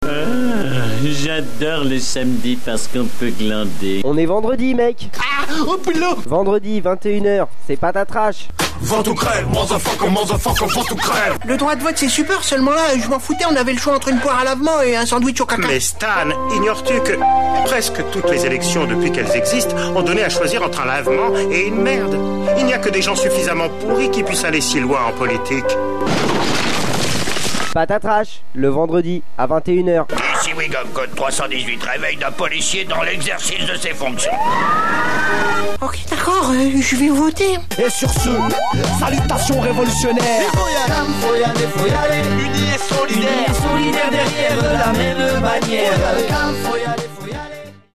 Fond musical
Jingle PATAvote
(voix south park et simpson)